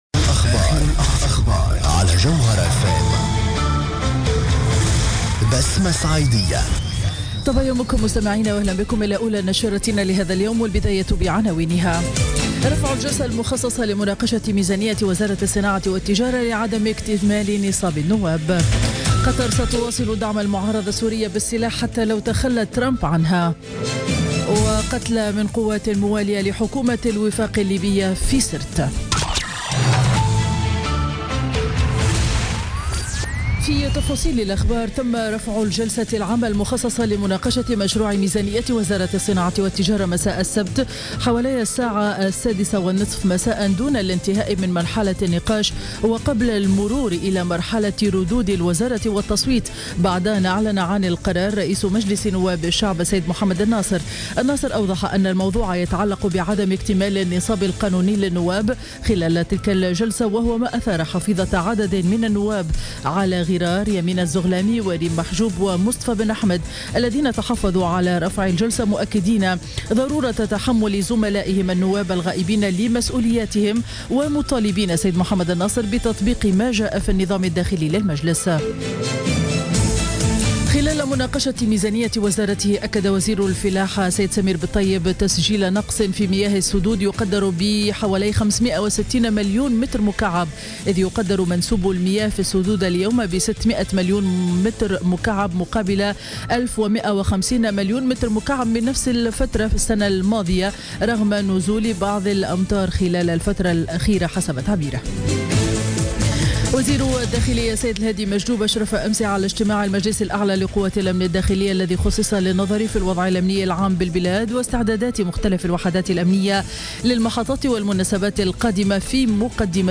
نشرة أخبار السابعة صباحا ليوم الأحد 27 نوفمبر 2016